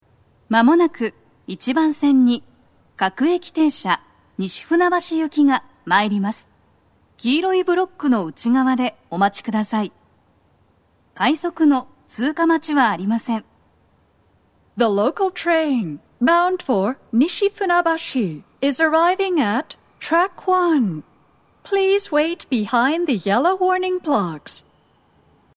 スピーカー種類 BOSE天井型
1番線の鳴動は、かなり遅めです。
女声
接近放送1